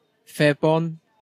Pentref yng nghymuned Arthog, Gwynedd, Cymru, yw Fairbourne[1][2] ("Cymorth – Sain" ynganiad ).